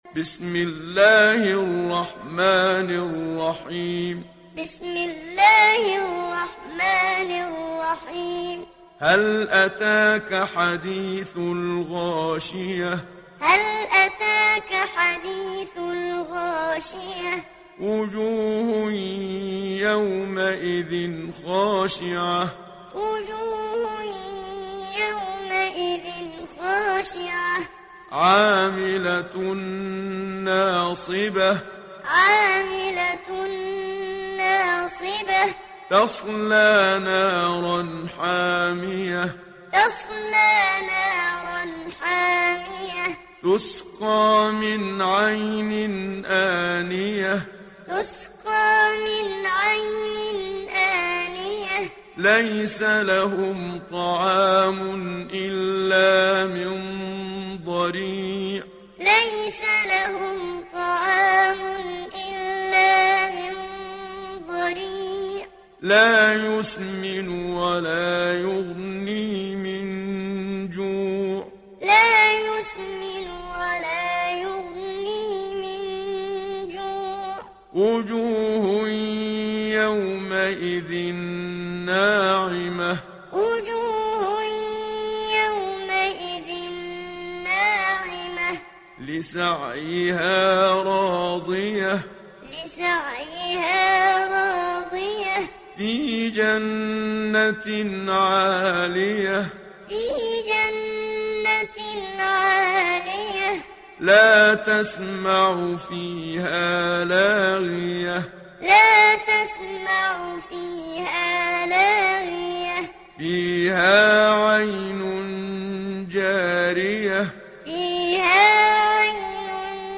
Gaşiye Suresi İndir mp3 Muhammad Siddiq Minshawi Muallim Riwayat Hafs an Asim, Kurani indirin ve mp3 tam doğrudan bağlantılar dinle
İndir Gaşiye Suresi Muhammad Siddiq Minshawi Muallim